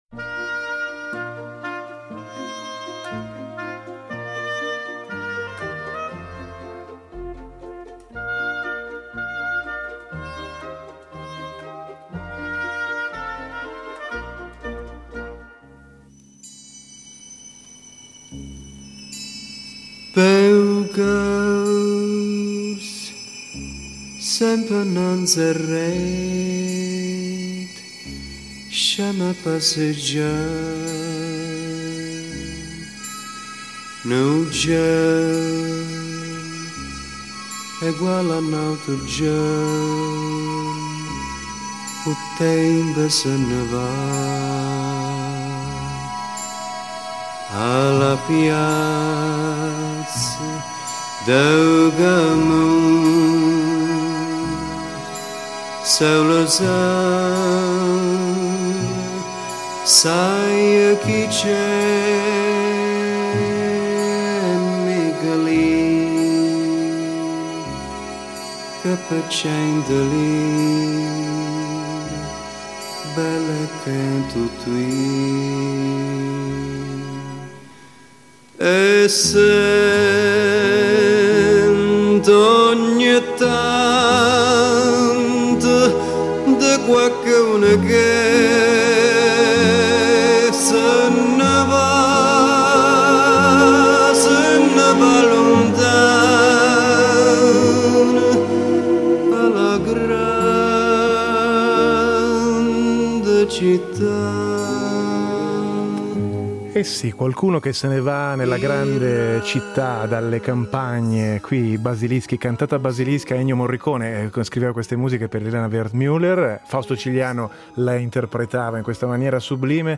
Tra storie, ascolti e testimonianze, l’eco di un’Italia che non vuole sparire.